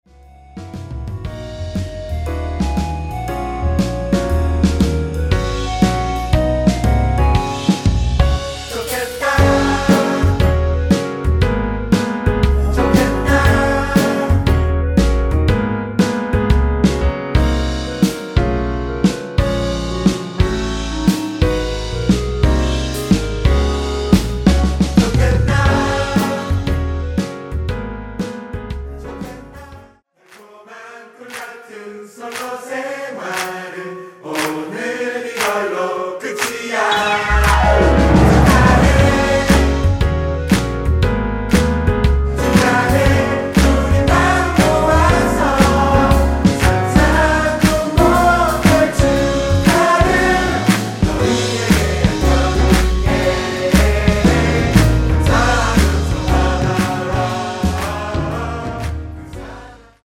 원키에서(-1)내린 멜로디와 코러스 포함된 MR입니다.(미리듣기 참조)
Gb
앞부분30초, 뒷부분30초씩 편집해서 올려 드리고 있습니다.
중간에 음이 끈어지고 다시 나오는 이유는
축가 MR